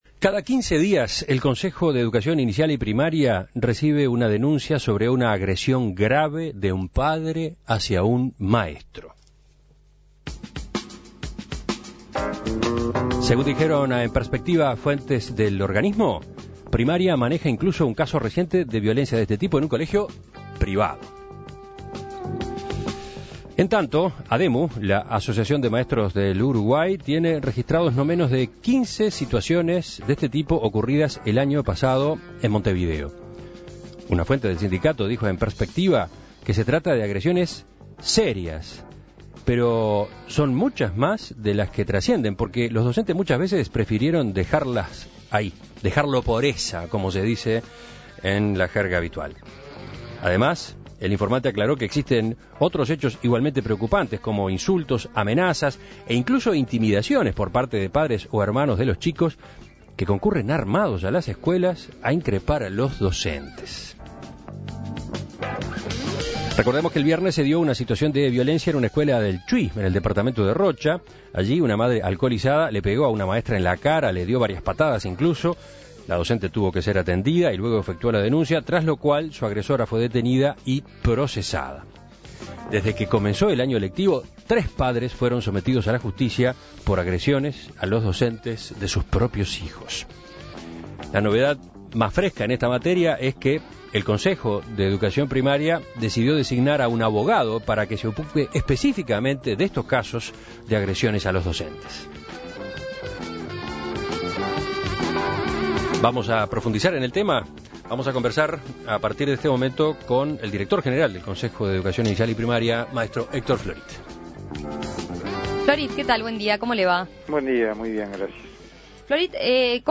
En las últimas semanas han sonado reiterados casos de violencia en entornos educativos. Se difundió por medio de la prensa que padres van al centro al que concurren sus hijos para propinar una paliza al docente del niño como forma de represalia. Para ahondar en esta problemática, conocer si es nueva o no y su gravedad, En Perspectiva dialogó con Héctor Florit, director general de Primaria.